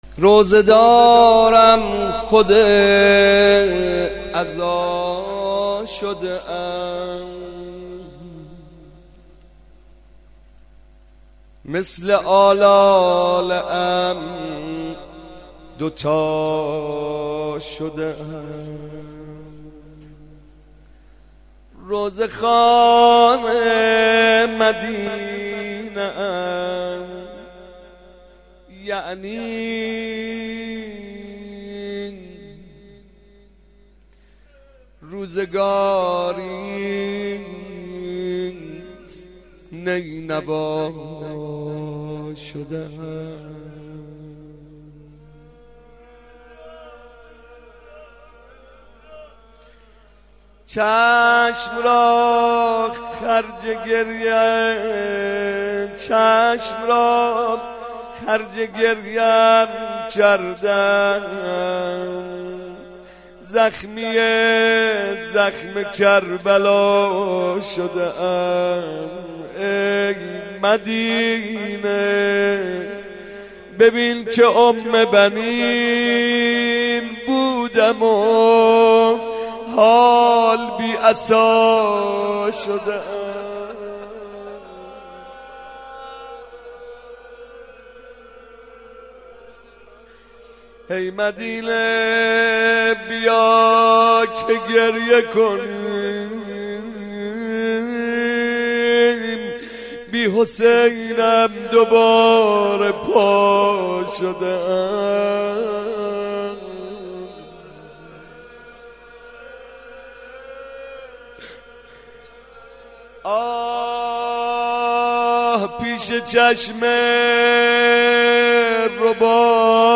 روضه و ذکر
komeil vafat hazrat omolbanin 03.mp3